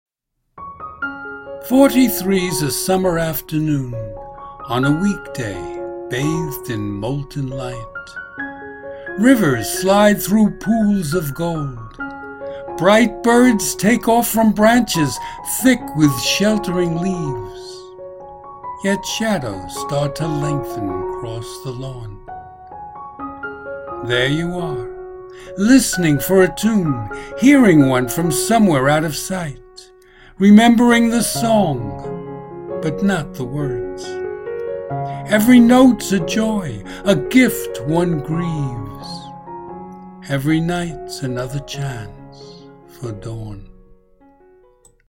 Hear me read the poem as an MP3 file.